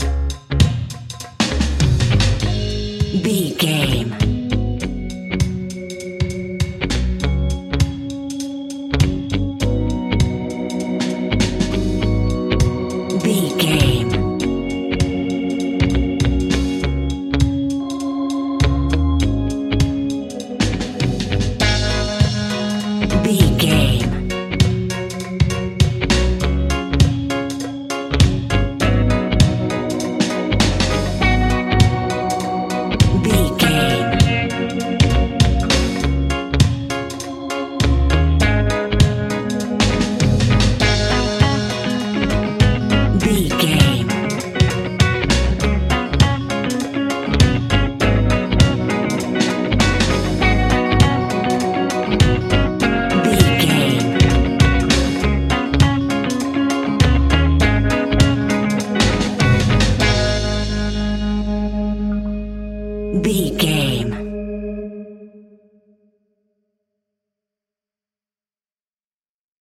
A nice bouncy and upbeat piece of Reggae music.
Aeolian/Minor
G#
Slow
laid back
off beat
drums
skank guitar
hammond organ
percussion
horns